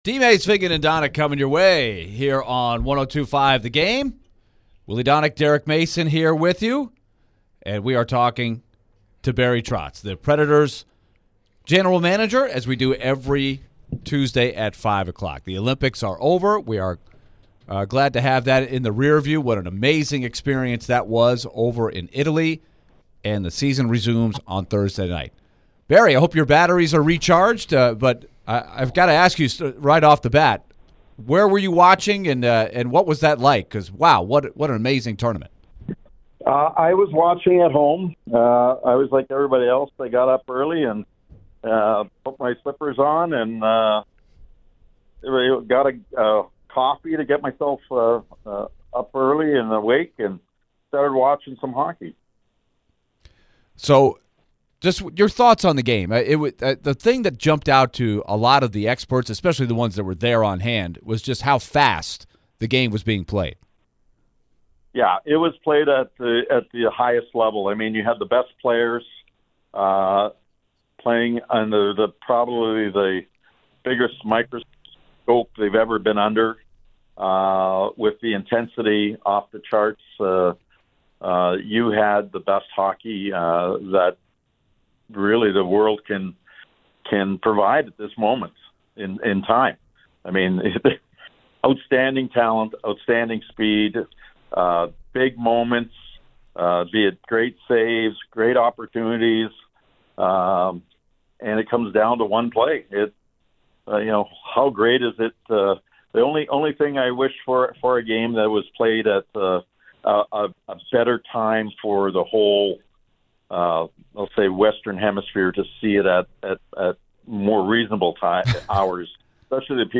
Nashville Predators General Manager Barry Trotz joins DVD for his weekly chat.